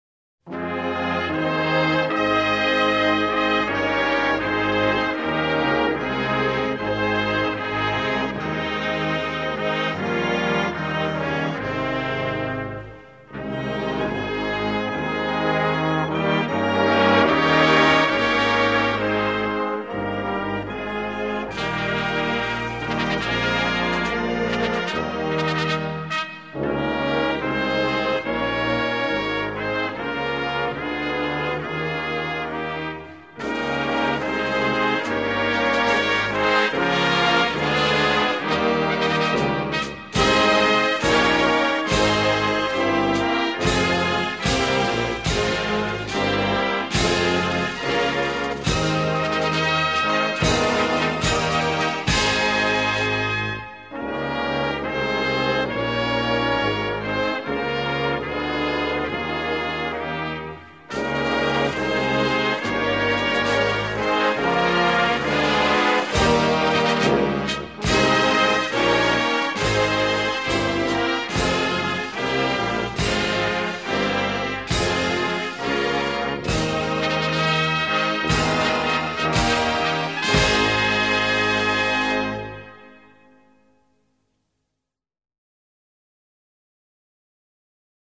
Melodie der Hymne